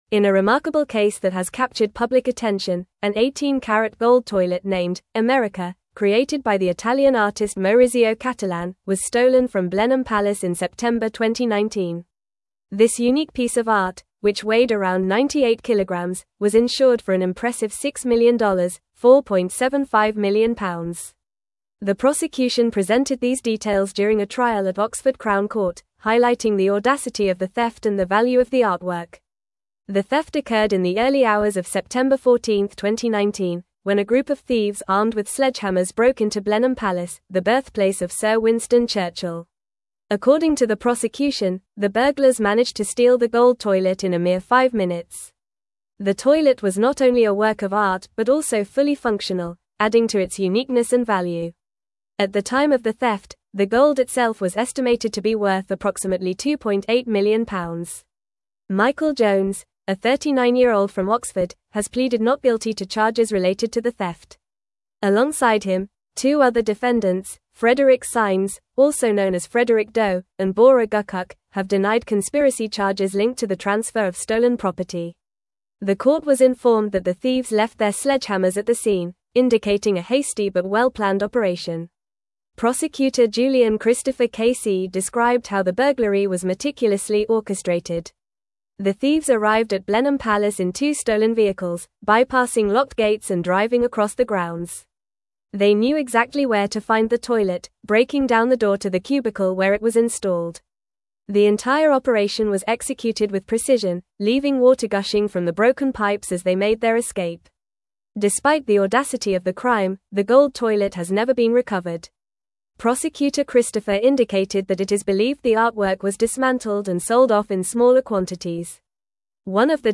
Fast
English-Newsroom-Advanced-FAST-Reading-The-Great-Gold-Toilet-Heist-at-Blenheim-Palace.mp3